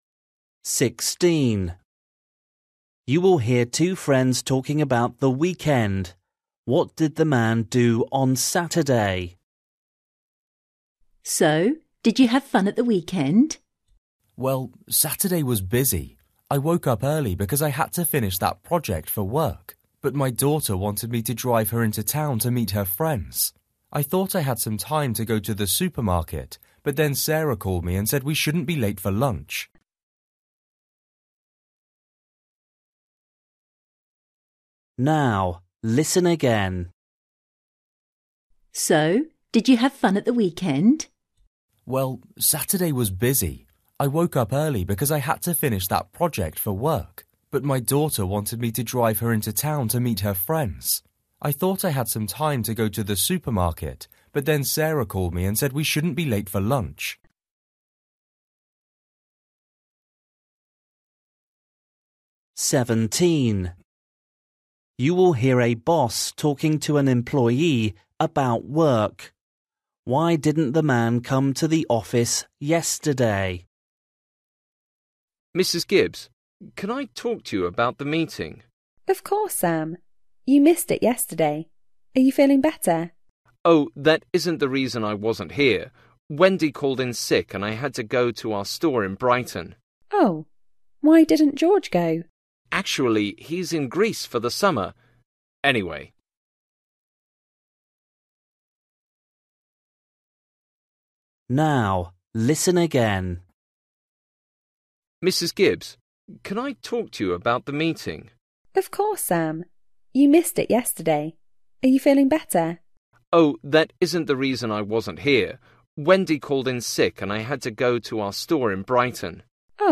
16   You will hear two friends talking about the weekend.
17   You will hear a boss talking to an employee about work.
18   You will hear a woman talking about sailing.